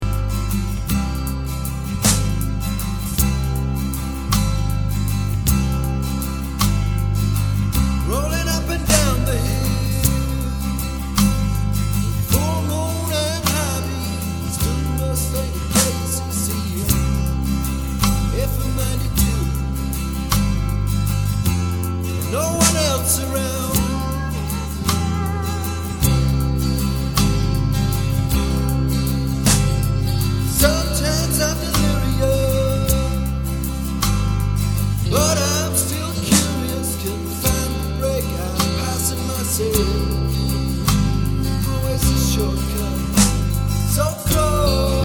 background vocals
acoustic guitar, Telemaster
bass
drums: QY-20